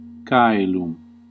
Ääntäminen
US : IPA : [ˈwɛðɚ]